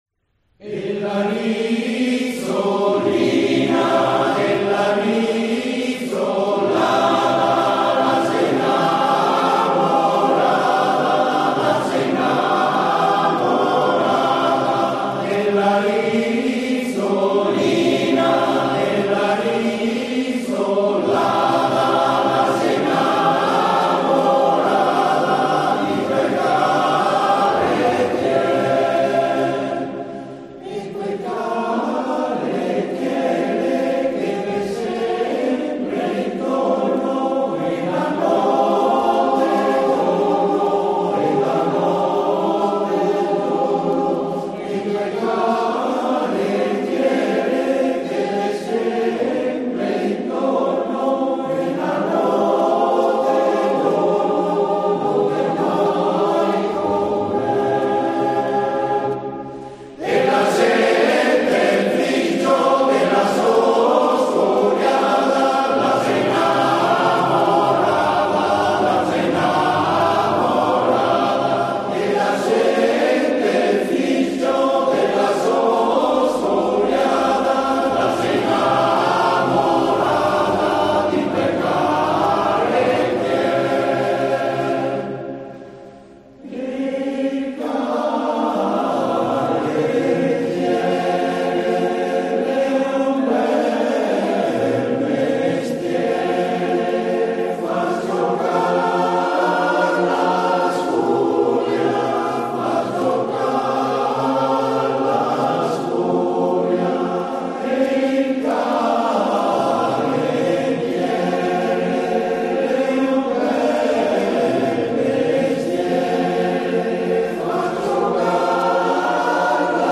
Coro LA GERLA di Spinea